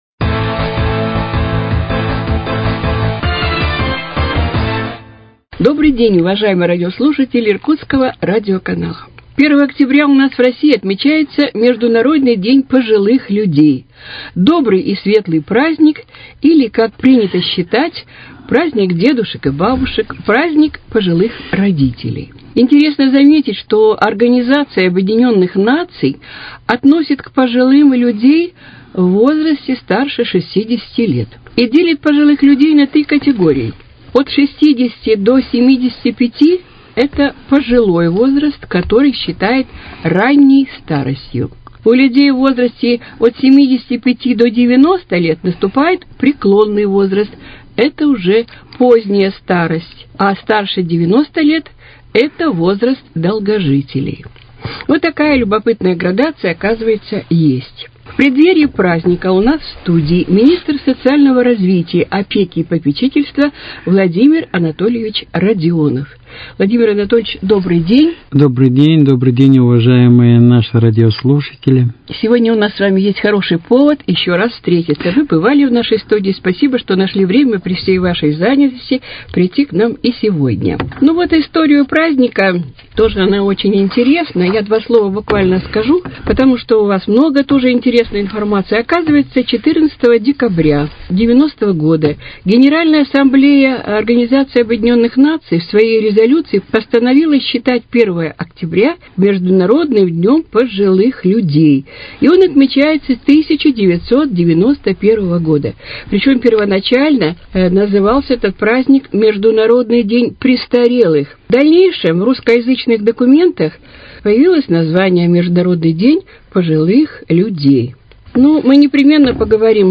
Актуальное интервью: О мерах социальной поддержки, предоставляемой людям пожилого возраста